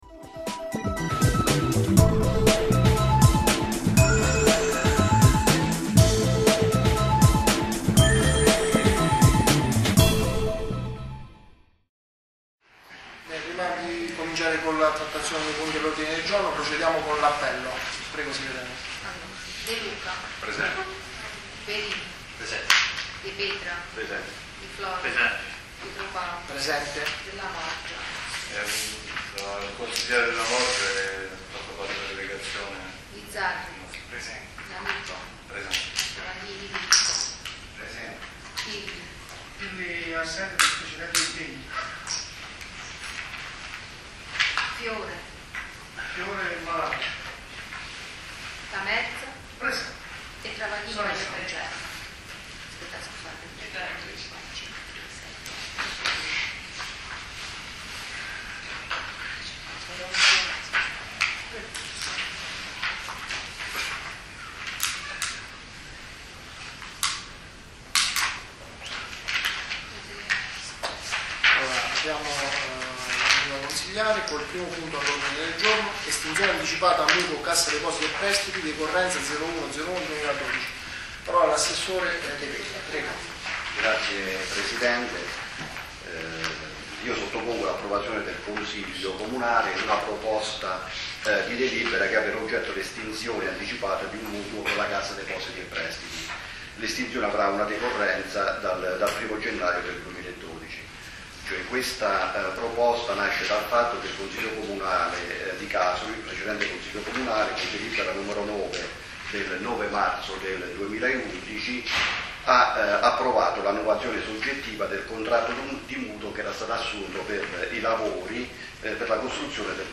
Ascolta il Consiglio Comunale del 24 Novembre 2011
Espone l'assessore. D. De Petra; risponde il consigliere di minoranza V. Lamelza; il consigliere P. D'Amico fa la sua dichiarazione di voto.